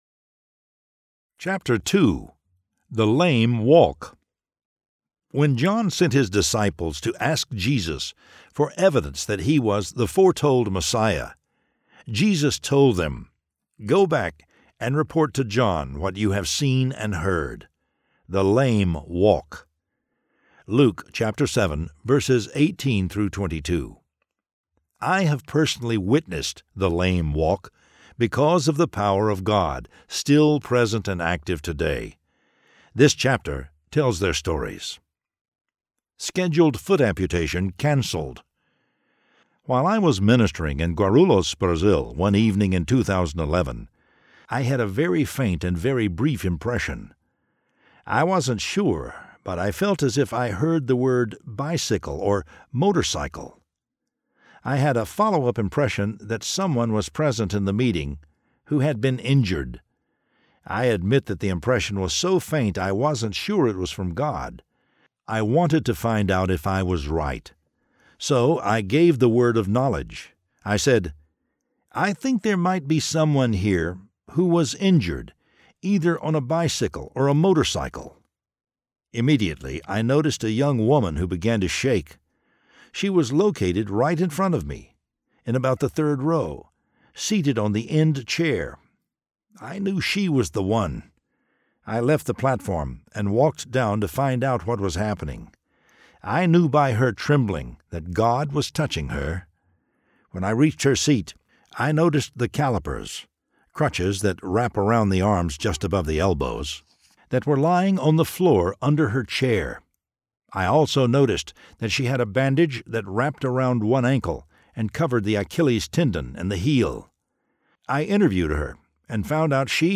Eyewitness to Miracles Audiobook